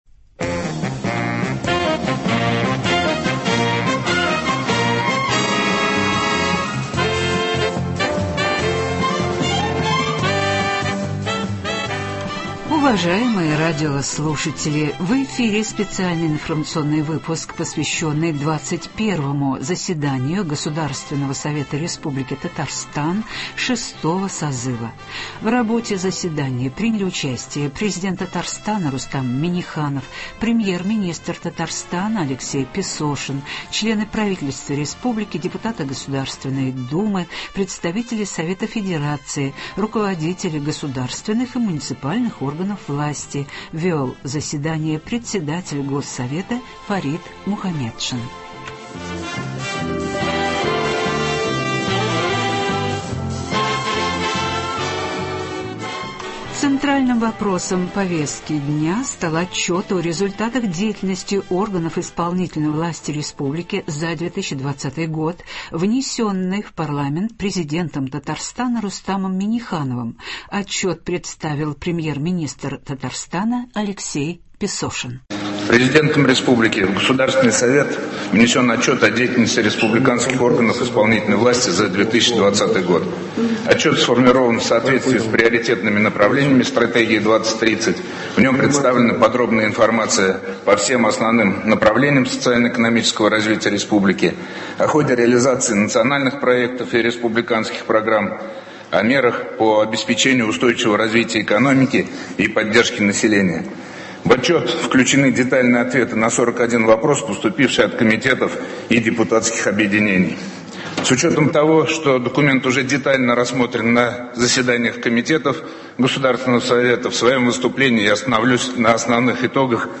В эфире специальный информационный выпуск , посвященный 21 заседанию Государственного Совета Республики Татарстан 6-го созыва.
Вел заседание Председатель Госсовета Фарид Мухаметшин.